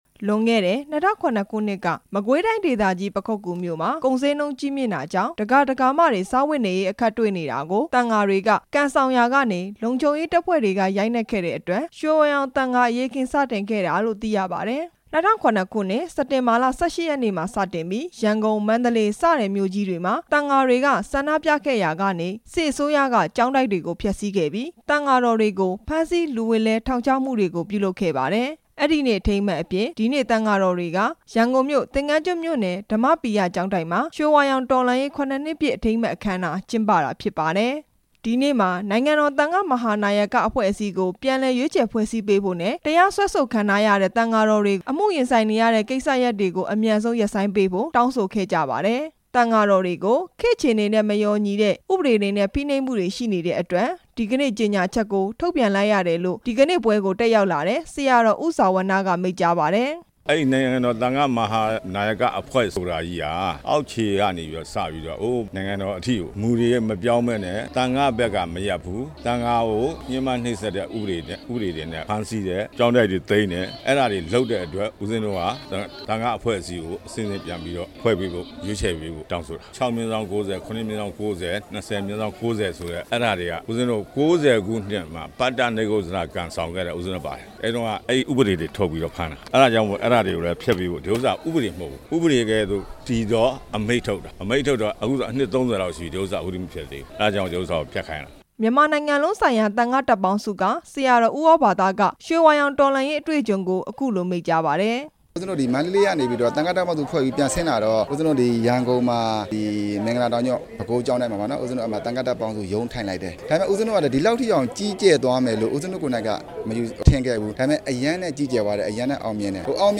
အခမ်းအနားအကြောင်း တင်ပြချက်